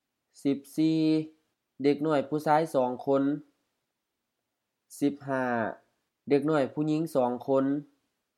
Isaan Pronunciation Tones